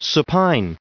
1025_supine.ogg